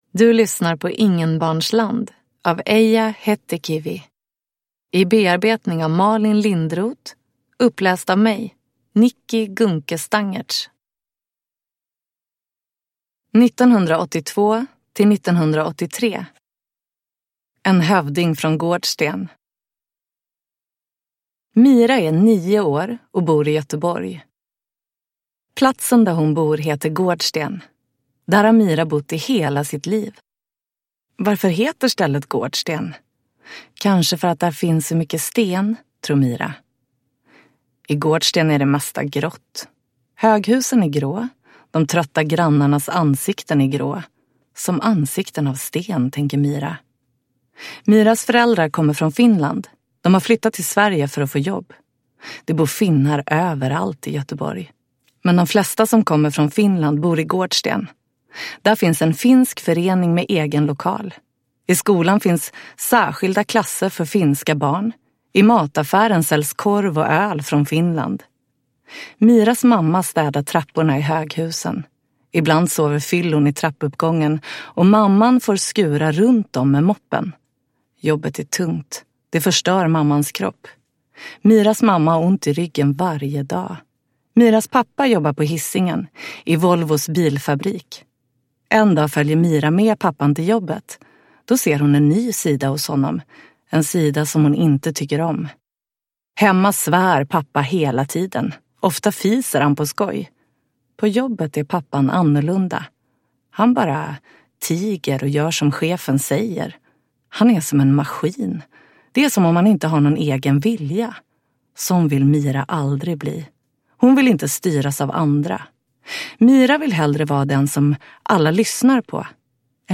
Ingenbarnsland (lättläst) – Ljudbok